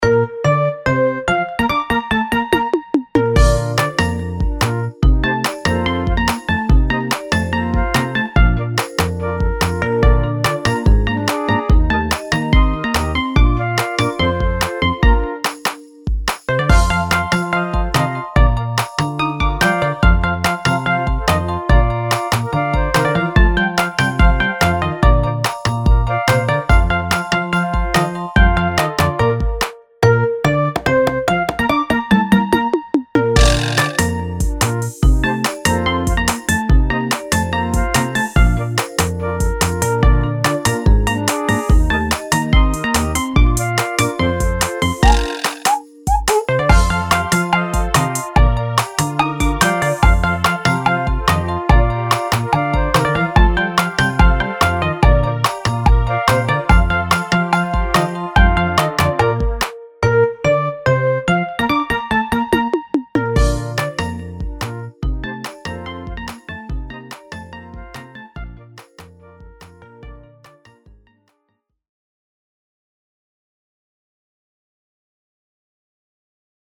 あかるい かわいい